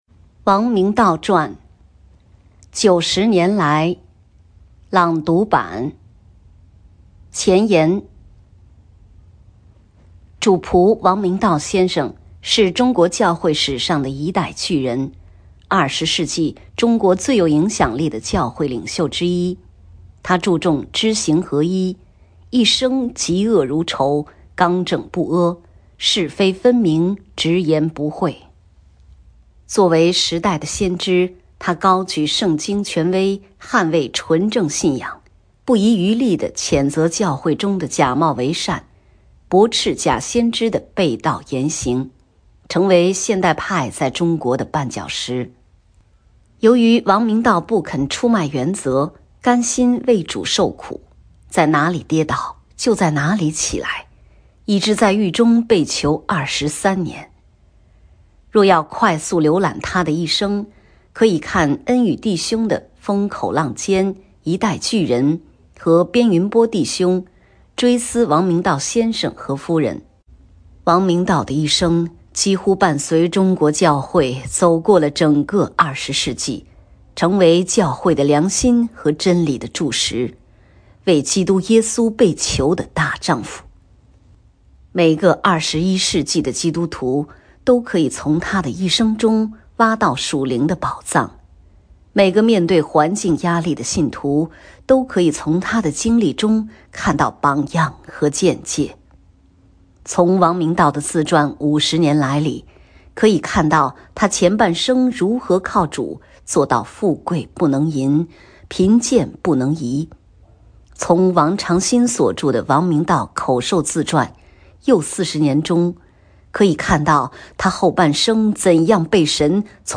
为了帮助阅读不便的读者，本事工把《五十年来》和《又四十年》综合制作成这份朗读版，并根据朗读长度的需要重新进行了分段。
本朗读版的结束曲是王明道先生亲自翻译的《主军前进》（词：Sabine Baring-Gould，曲：Arthur S. Sullivan）。